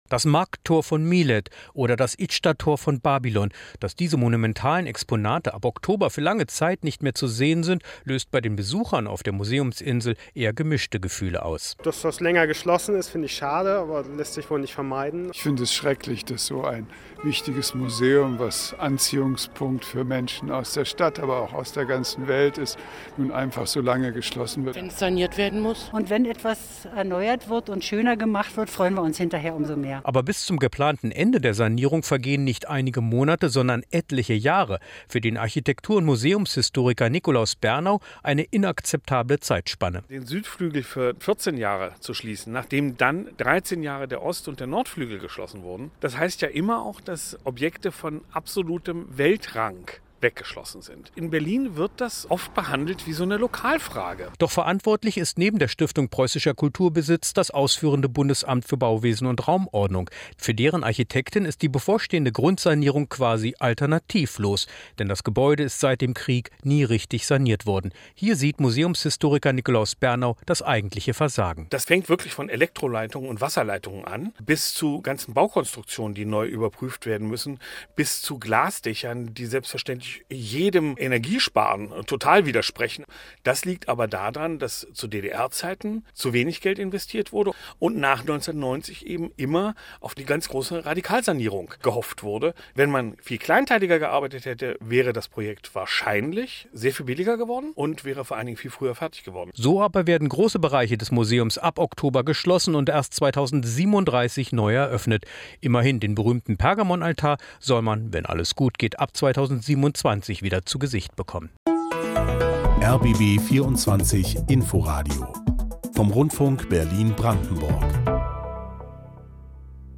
Museumsinsel: Stimmen zum Umbau am Pergamonmuseum